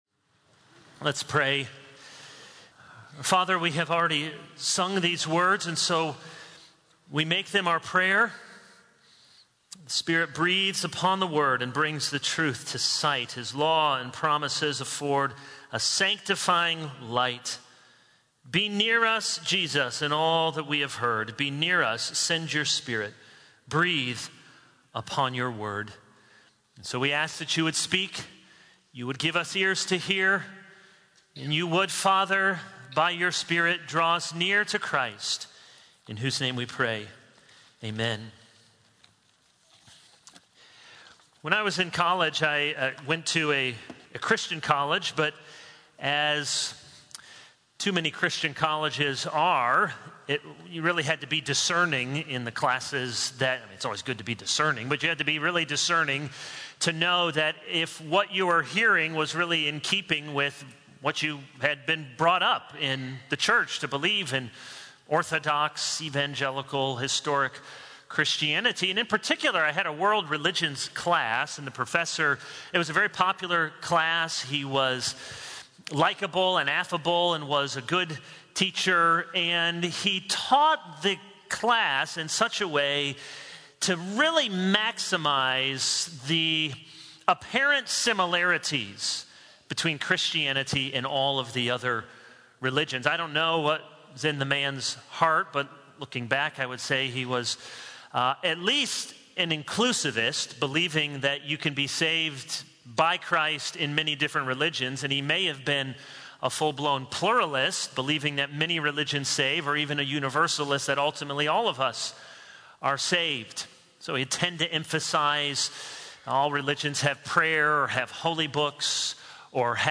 This is a sermon on 1 John 5:6-12.